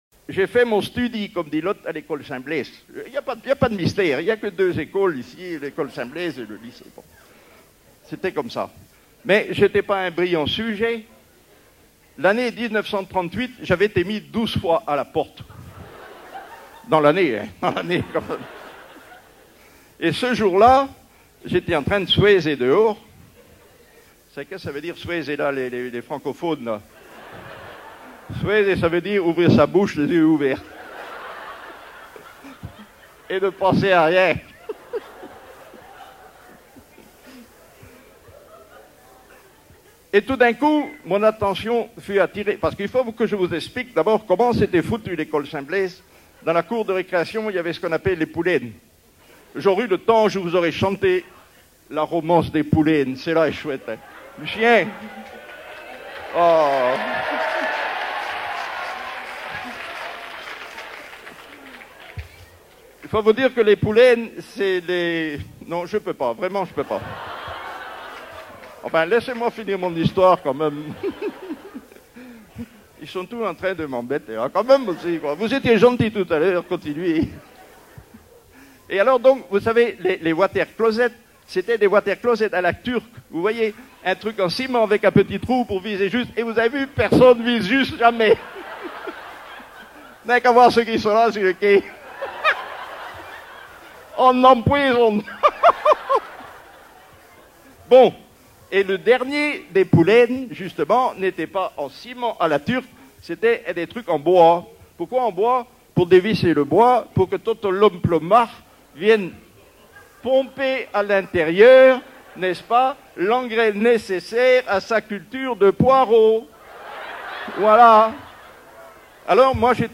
une des nombreuses histoire douarneniste racontée lors de la veillée du 12 août 1988
Genre sketch
Catégorie Récit